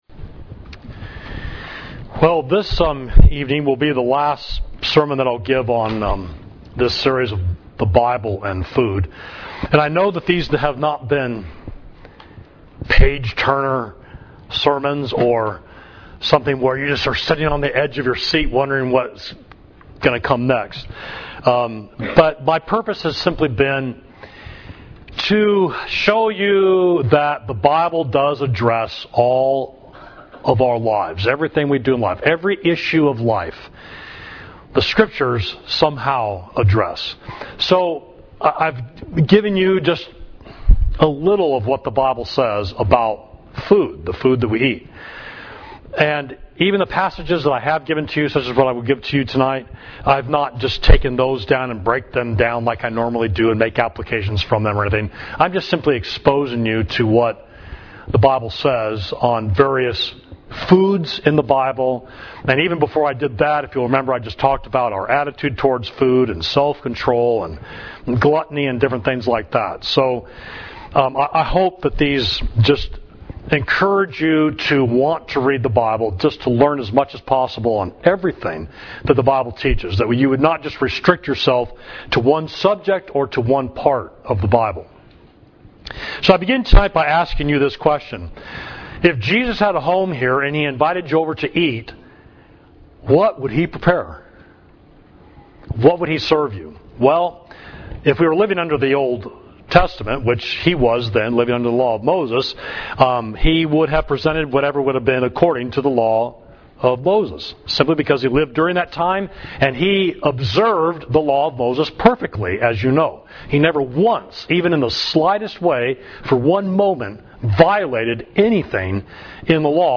Sermon: The Bible and Food, Part 4 – Savage Street Church of Christ